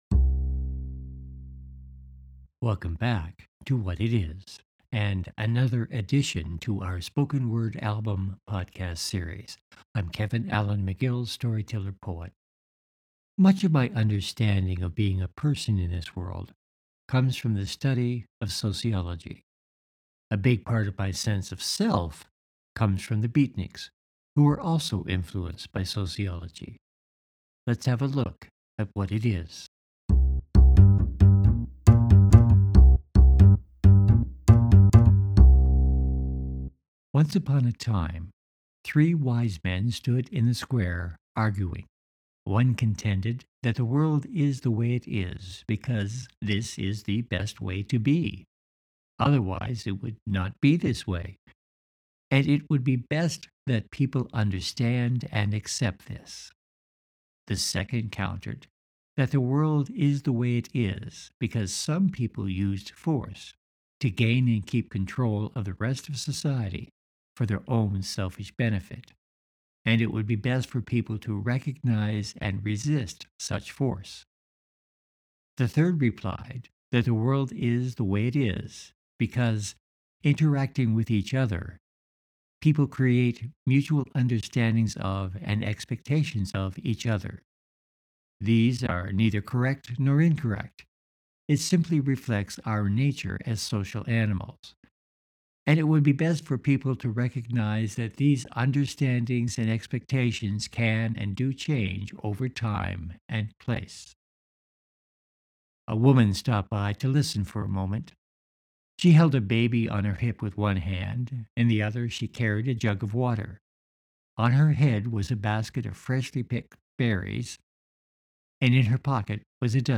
Let’s continue with our episode to episode Spoken Word album This episode introduces the four paradigms of Sociology and The Beatniks. And proposes the idea of getting together with others, online and in person, to create a community of understanding and respect for our humanity.